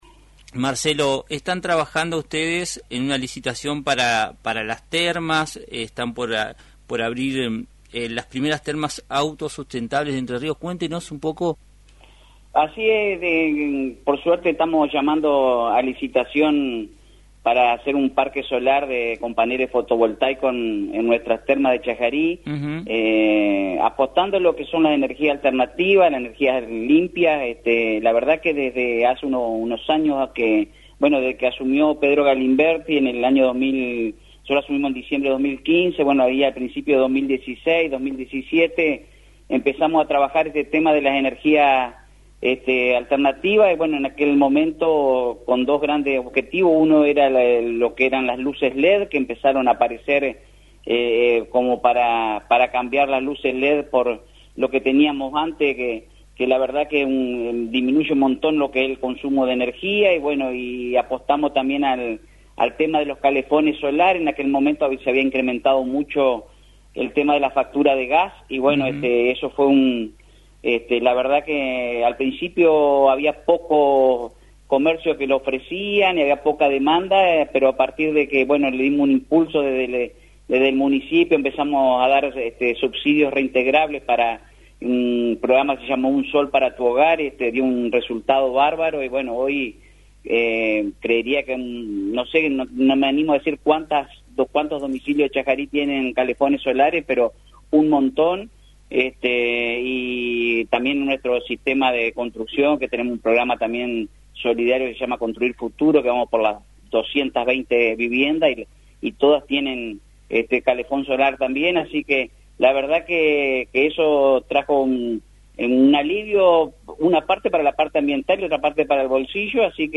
El Intendente de la localidad entrerriana, Marcelo Borghesan, entrevistado en el programa “Puntos Comunes”, destacó los programas de viviendas sustentables impulsados por el municipio, como “Construir Futuro”, que ya suma 220 casas construidas con calefones solares, ofreciendo una alternativa más económica y ecológica para sus habitantes.